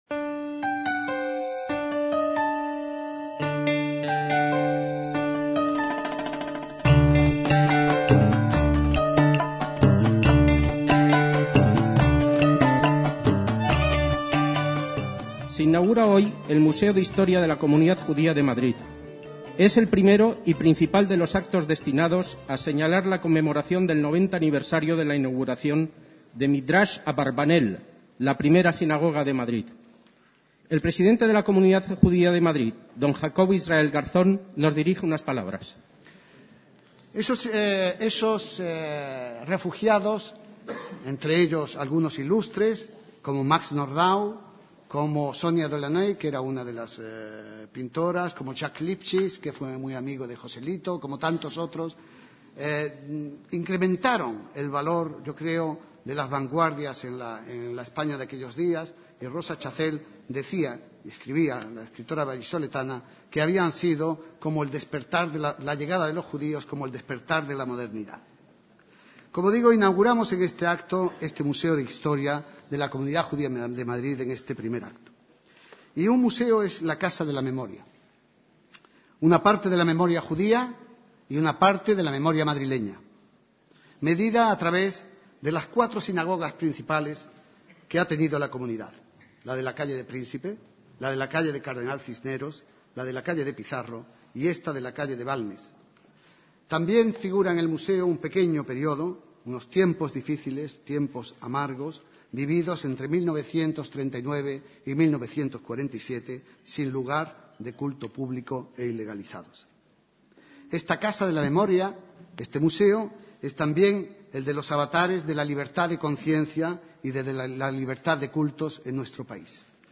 DECÍAMOS AYER (1/3/2007) - A principios de 2007, la Comunidad Judía de Madrid inauguraba en su sede un pequeño museo sobre su propia histora. Al evento acudieron numerosos invitados vinculados a la institución.